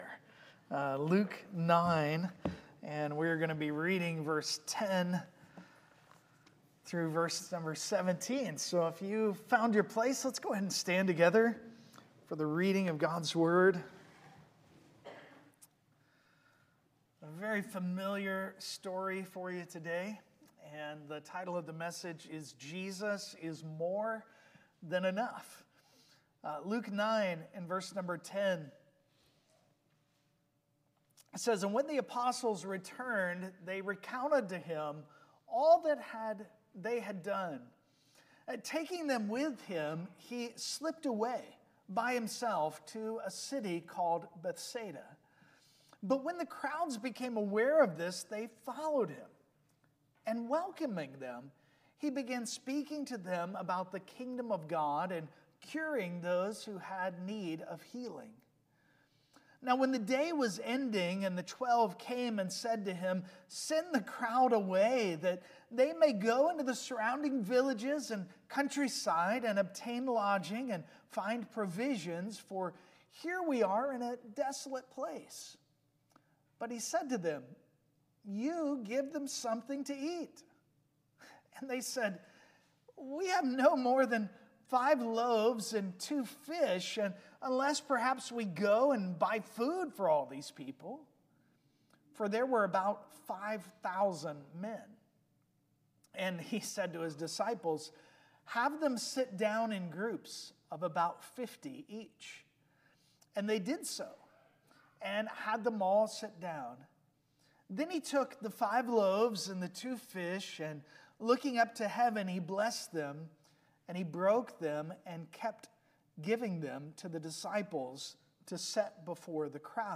Passage: Luke 9:10-17 Service Type: Sunday Morning